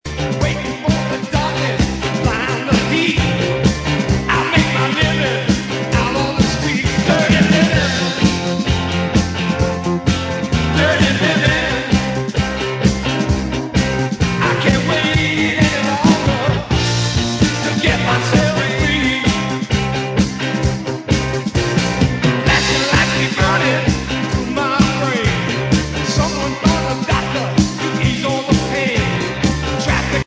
lead vocals, drums
guitar, bass